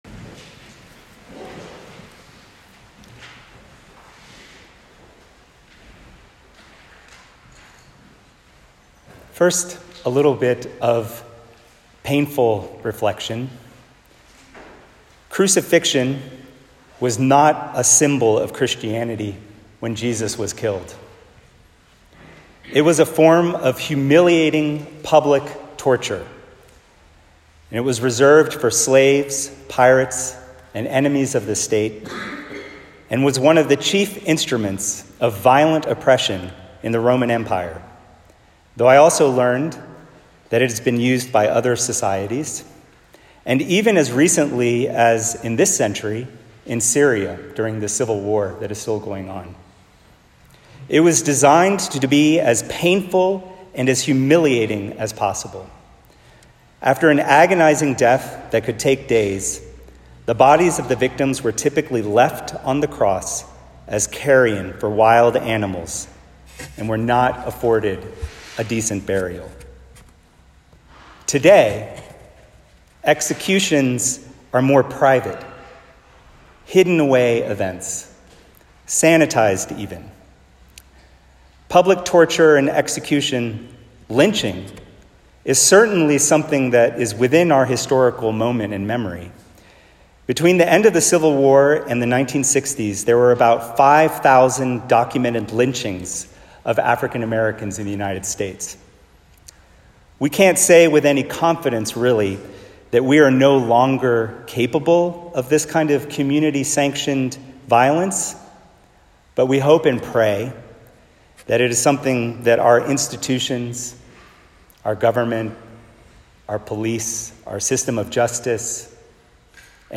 A Sermon for Christ the King Day - St. James' Episcopal Church Mt. Airy MD
A Sermon for Christ the King Day